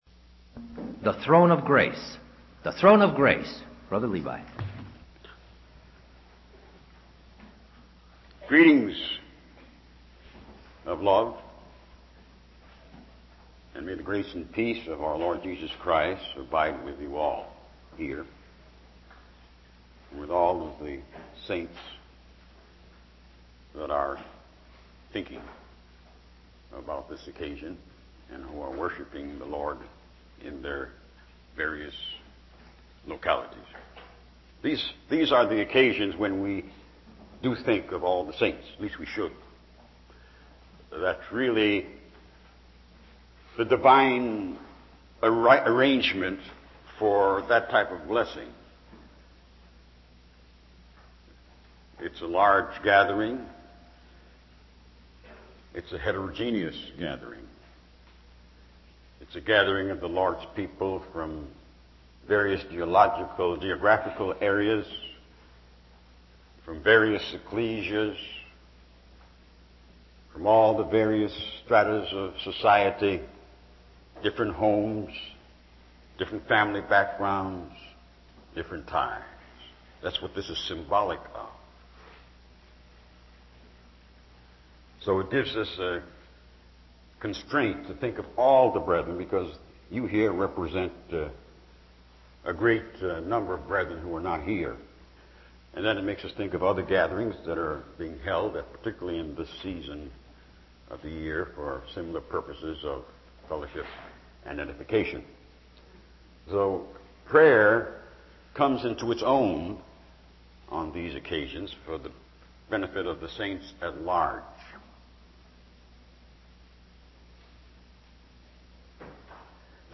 From Type: "Discourse"
Indiana-Ohio Convention 1988